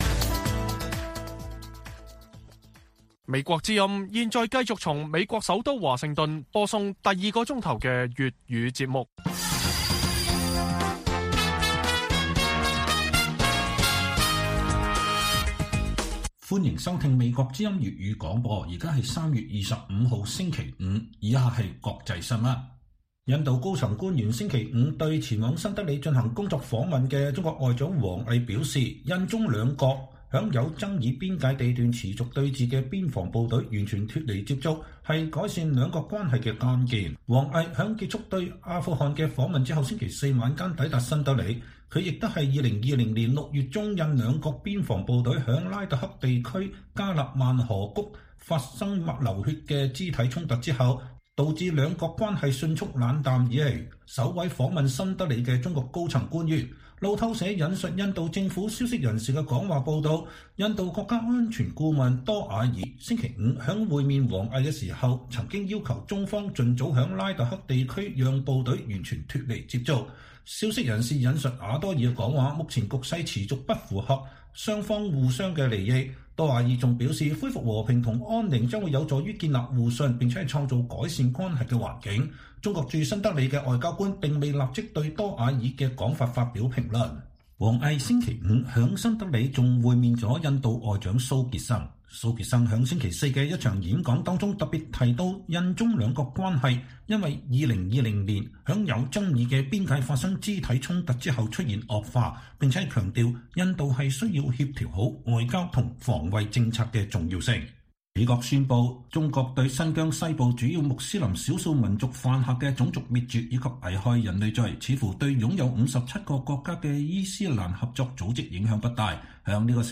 粵語新聞 晚上10-11點：北約峰會開幕，拜登與歐洲盟友討論強化施壓俄羅斯措施